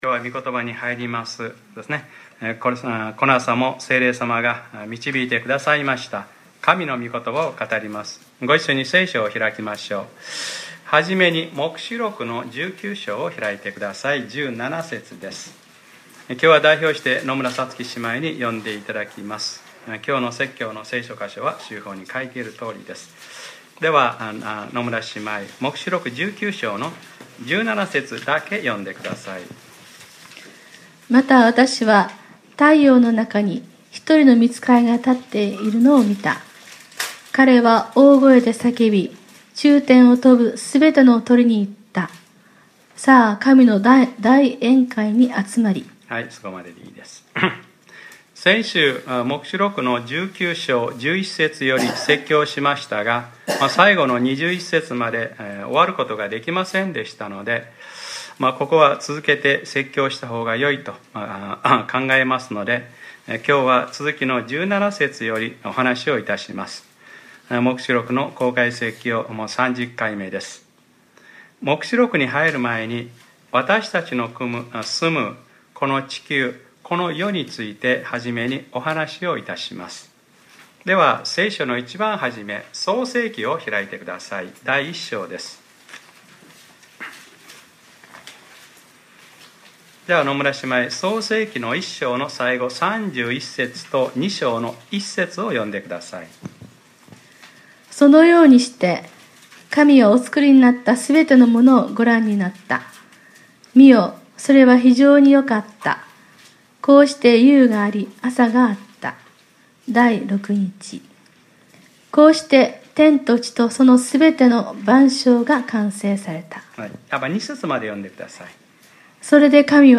2014年6月 1日（日）礼拝説教 『黙示録ｰ３０：生きたまま投げ込まれた』